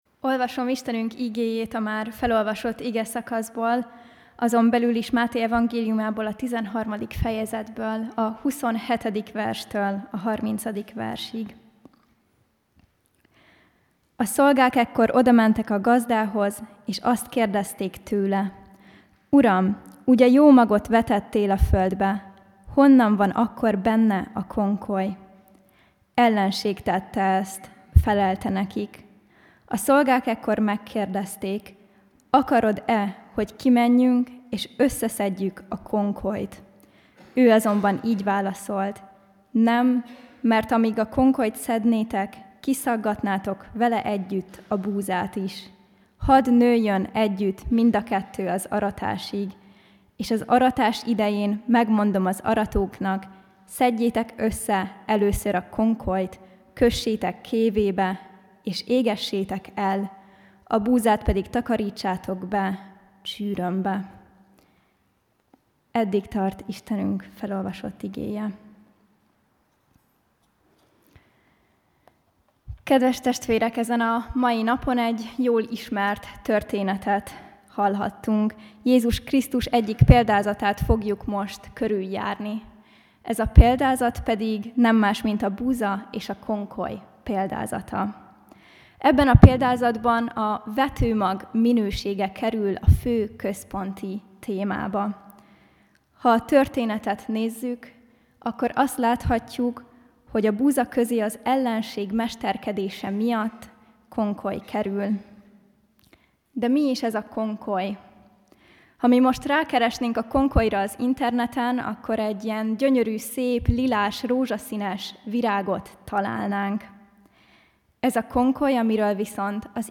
AZ IGEHIRDETÉS MEGHALLGATÁSA